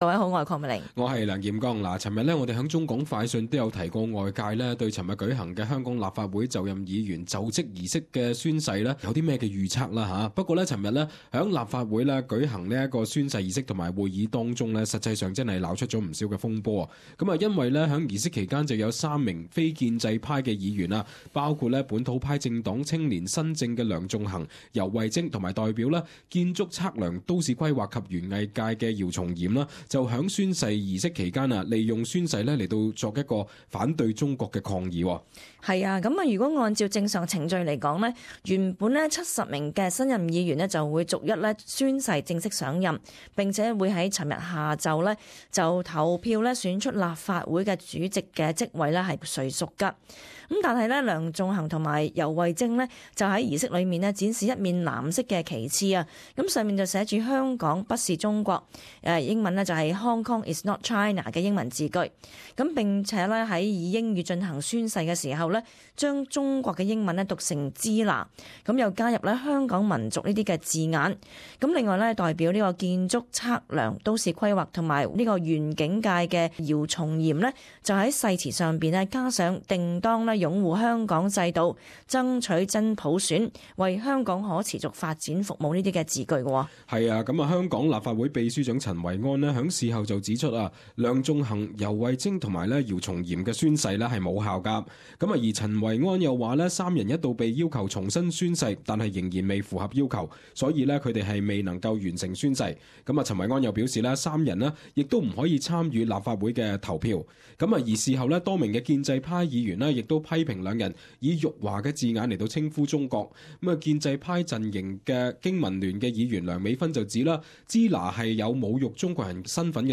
【时事报导】香港立法会宣誓风波